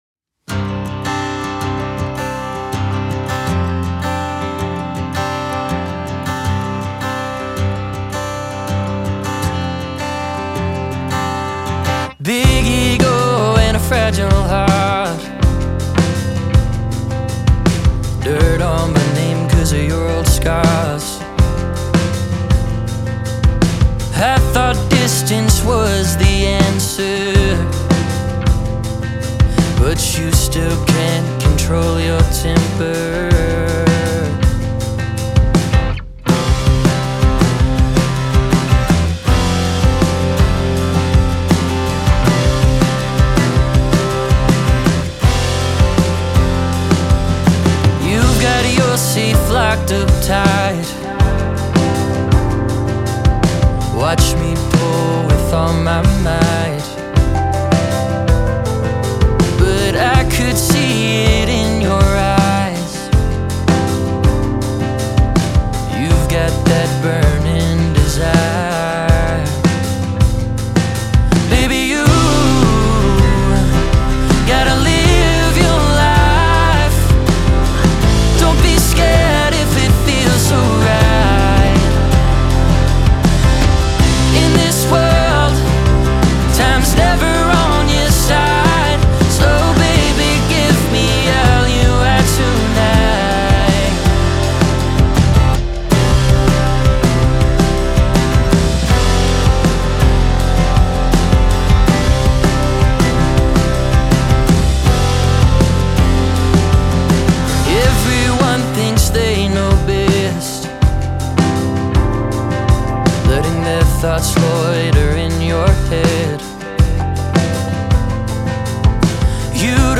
"Standing Still" (rock)